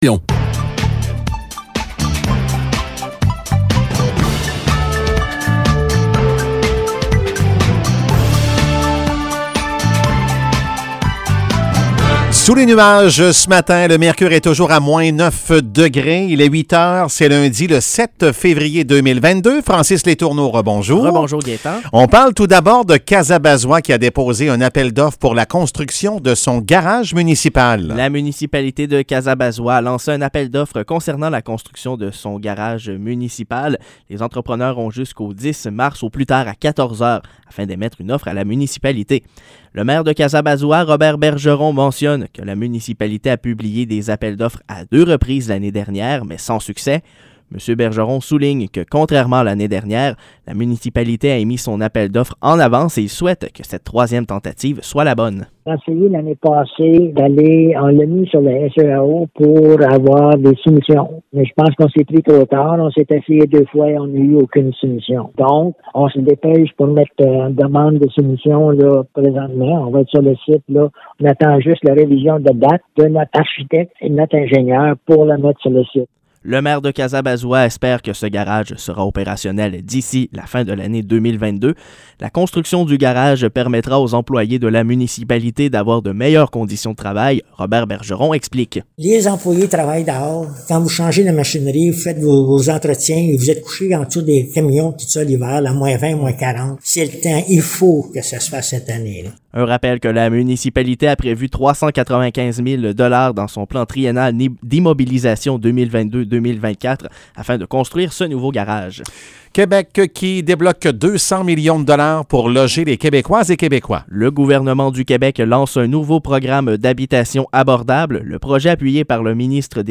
Nouvelles locales - 7 février 2022 - 8 h